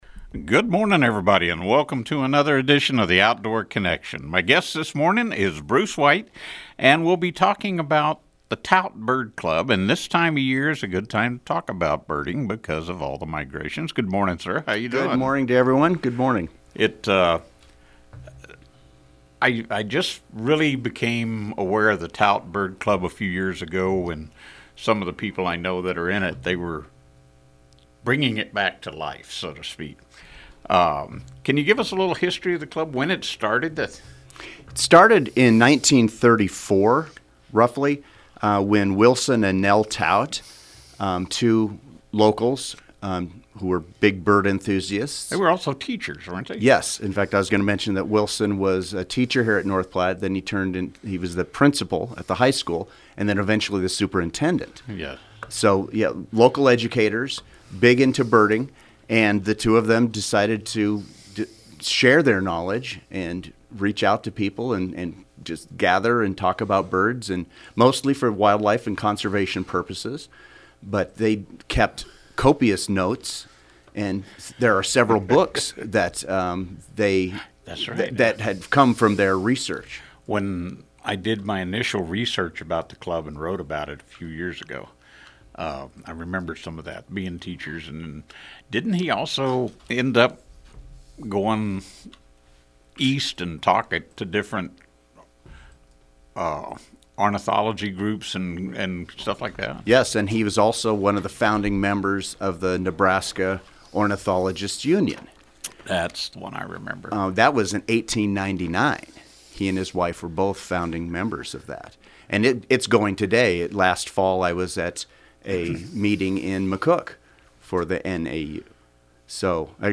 as heard on 1240-AM/106.1-FM, KODY Friday mornings at 8:30 AM.